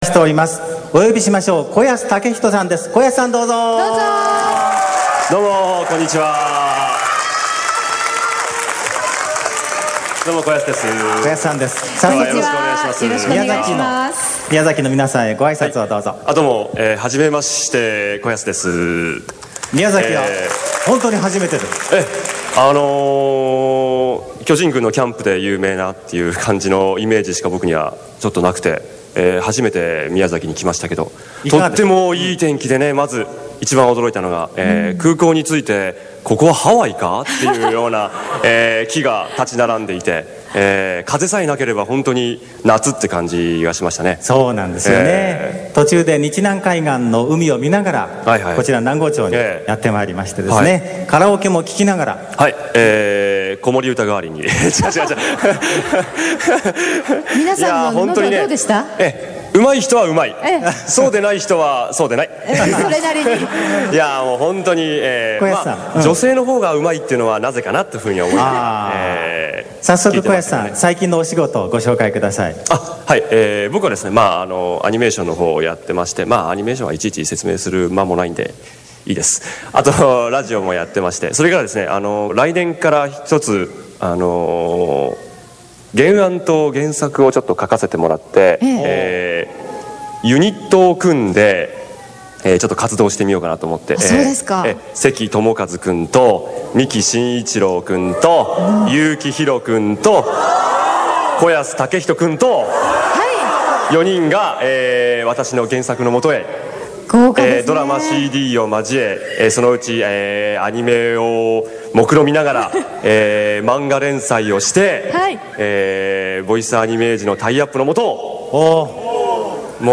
１９９６年１２月、南郷町のハートフルセンターで日南線にのってアニメトレインで公録をたのしもう！という面白い企画があった。ゲストは神谷 明さんと子安武人さん、ふしぎ遊戯の星宿、勇者指令ダクオンで活躍の子安武人さんにインタビューした。